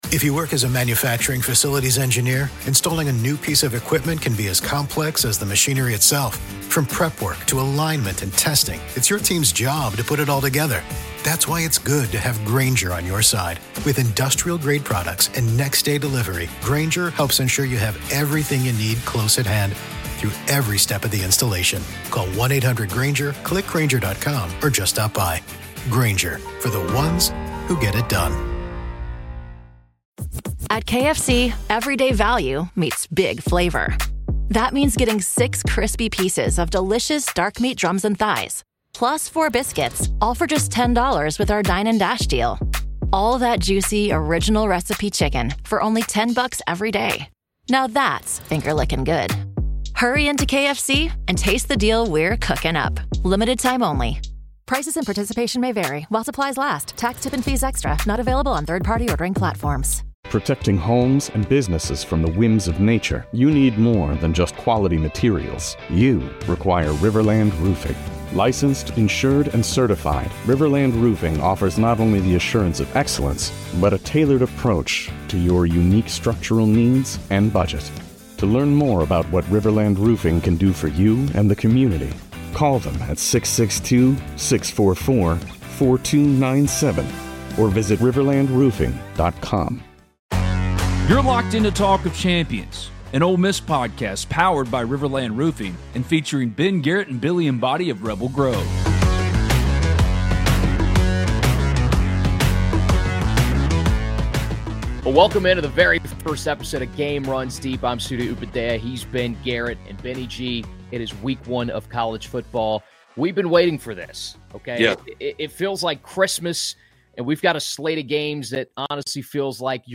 This week's episode is another special recorded at IBMA's World of Bluegrass in Chattanooga. I have four shorter interviews for you, each with someone connected to Dark Shadow Recording.